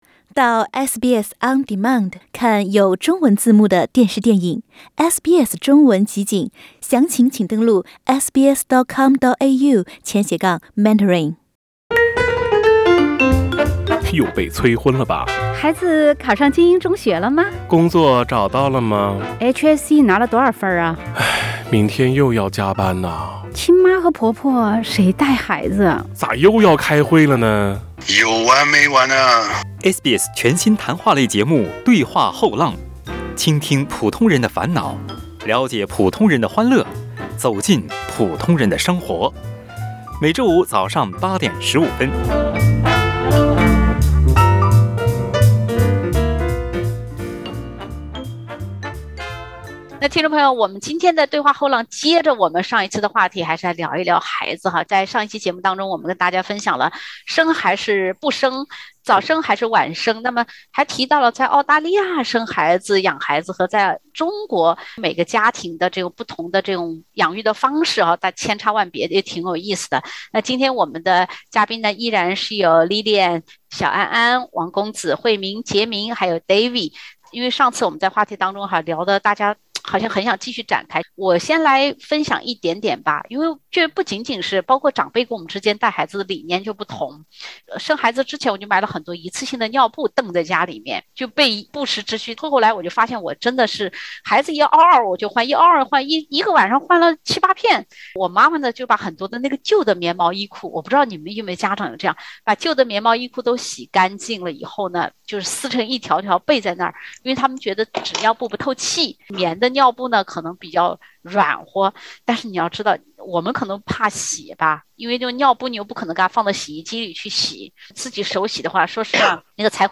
当知识武装到牙齿的“新青年”们面对“有一种，叫你妈觉得你冷”的育儿老经验。（点击封面图片，收听多人有趣谈话）